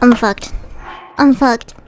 Worms speechbanks
Byebye.wav